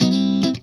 FUNKNCHUNK6L.wav